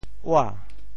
倚 部首拼音 部首 亻 总笔划 10 部外笔划 8 普通话 yǐ 潮州发音 潮州 ua2 白 i2 文 中文解释 倚 <动> (形声。
ua2.mp3